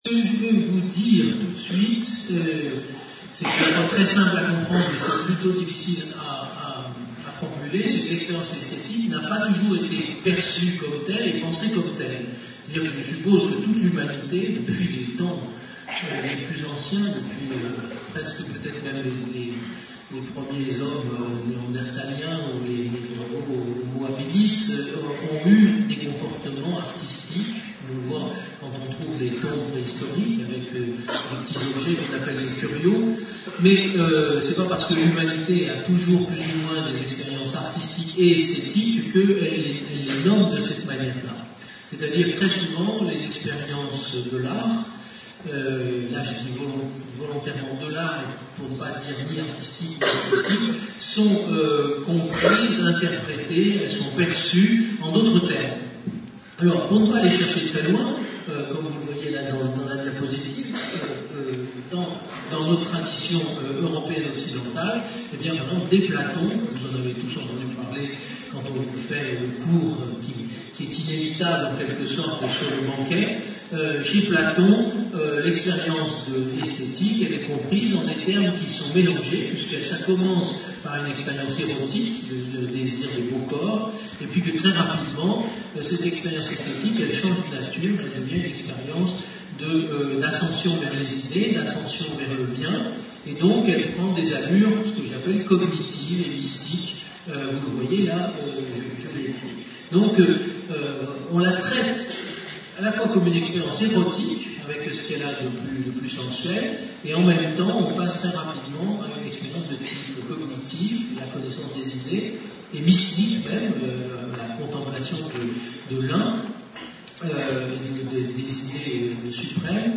Mais de quoi sont faites ces expériences ? Professeur de philosophie, Yves Michaud répond. Une conférence de l'UTLS au lycée avec Yves Michaud.